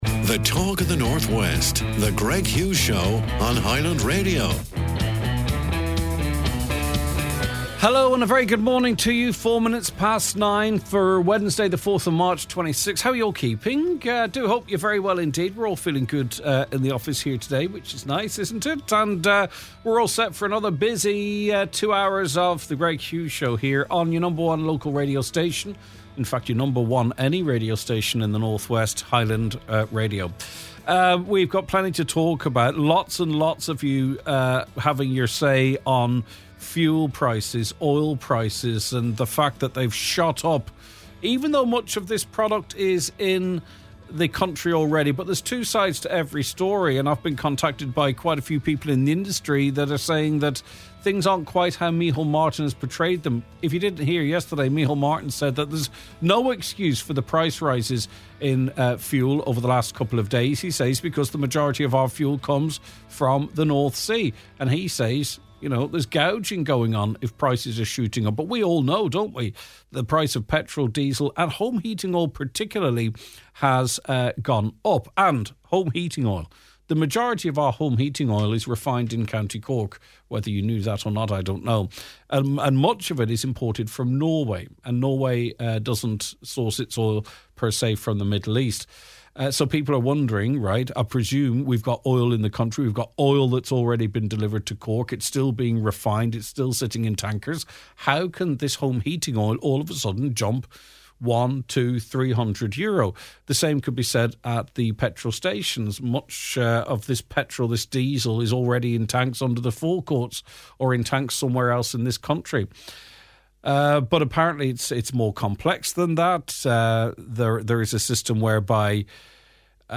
Listener Response : We hear from you.